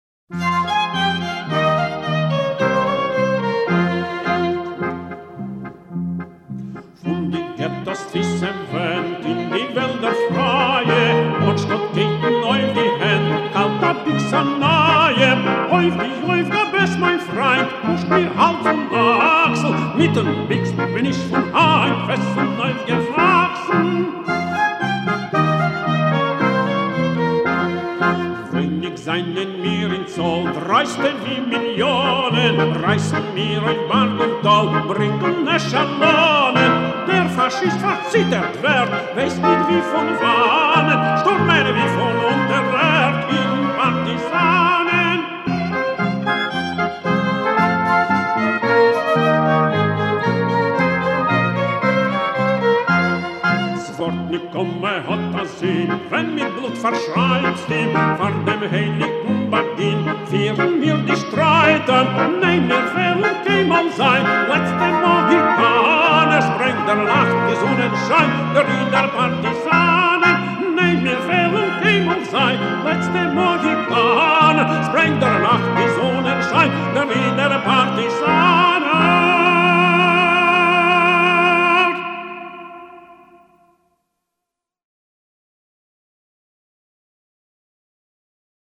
Интересная версия на идиш, в исполнении кантора